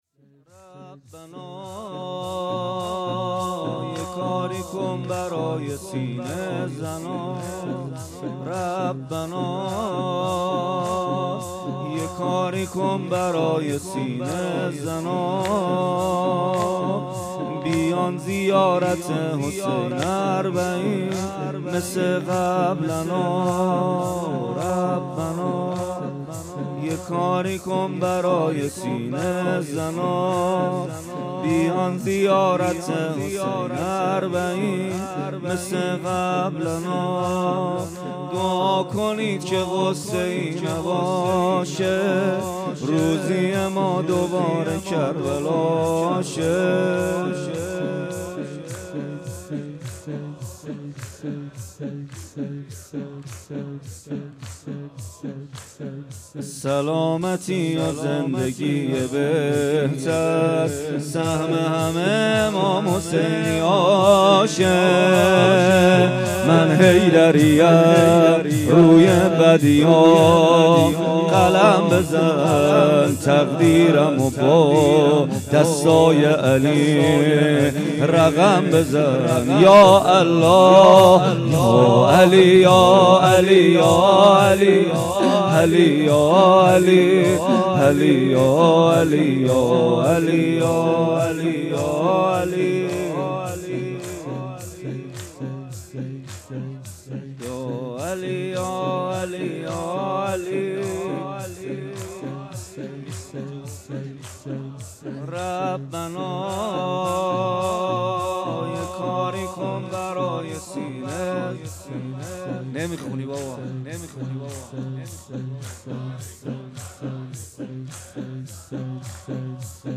شب بیستم ماه مبارک رمضان ریحانه النبی